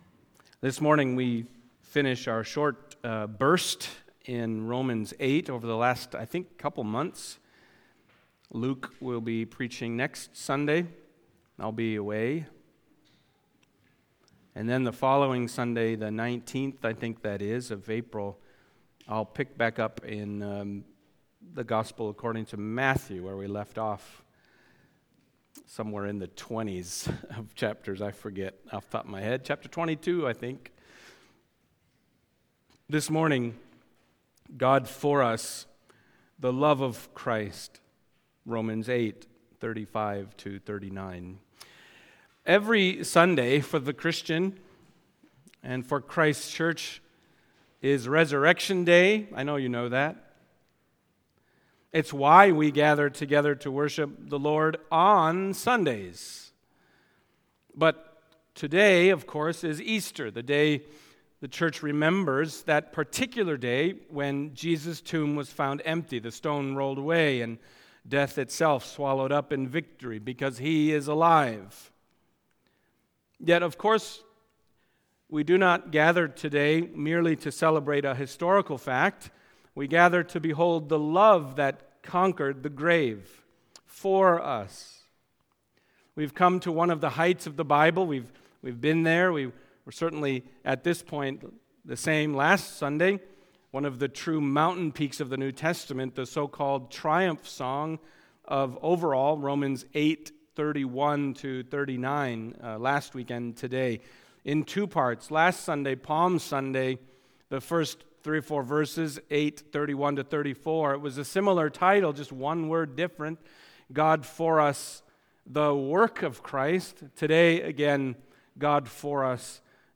Other Passage: Romans 8:35-39 Service Type: Sunday Morning Romans 8:35-39 « God For Us